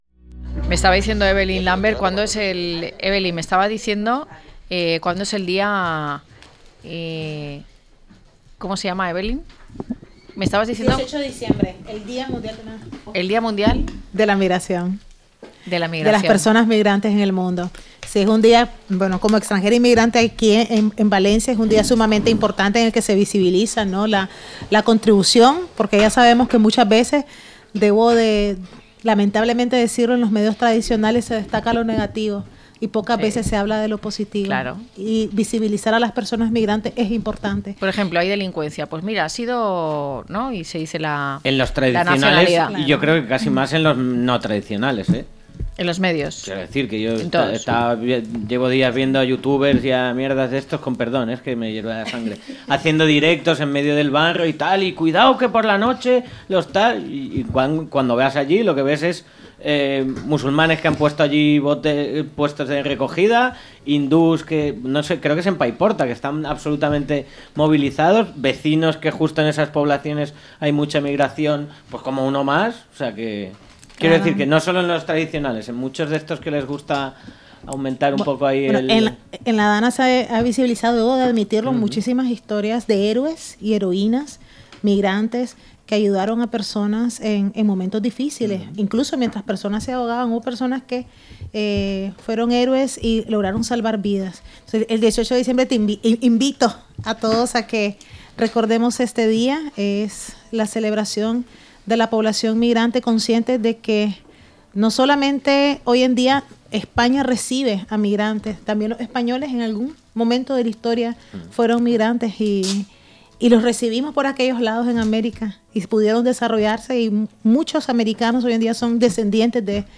1121-LTCM-DEBATE.mp3